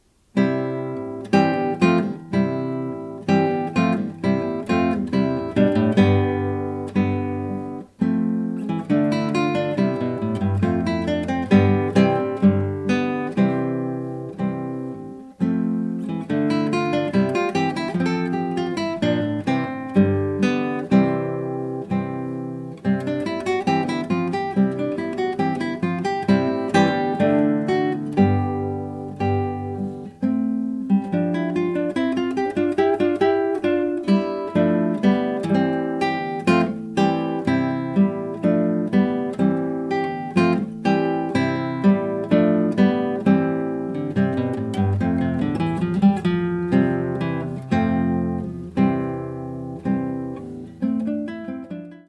Classical Guitar